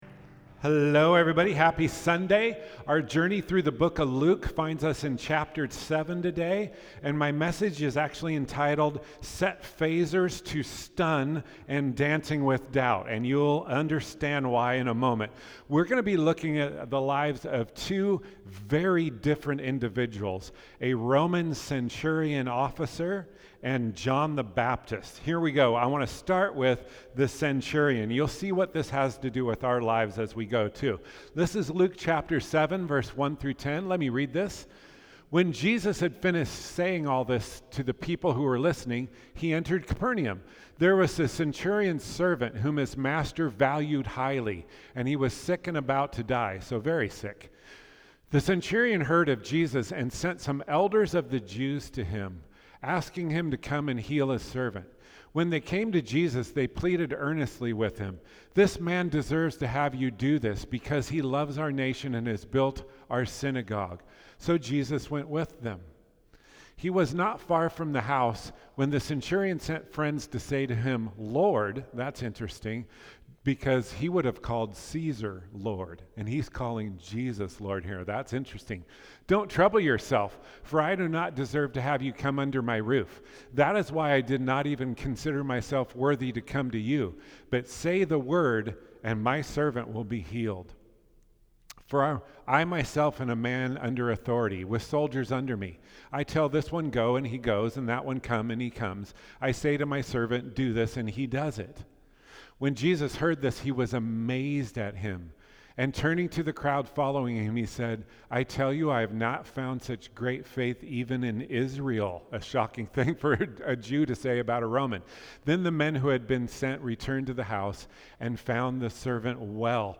Sermons | Faith Avenue Church